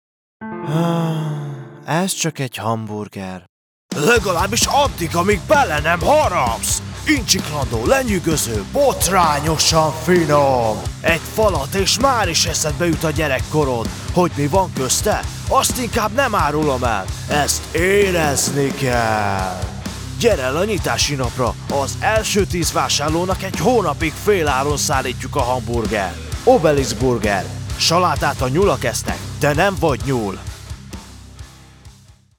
1002HungarianCommercialDemo.mp3